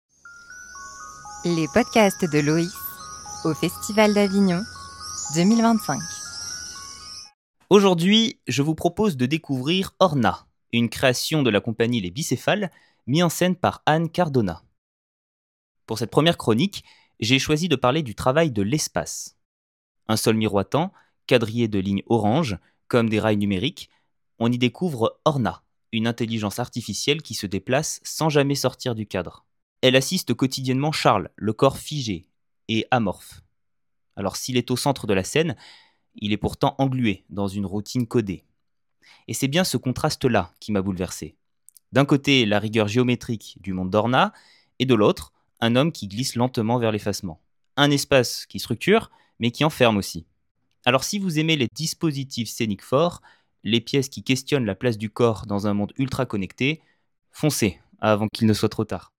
Découvrez la chronique audio de la pièce Orna au Festival Avignon 2025.